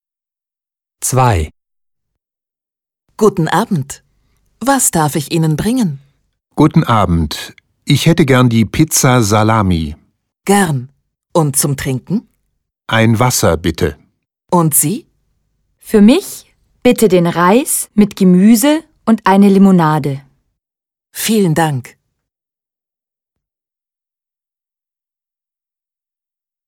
Dialog 2: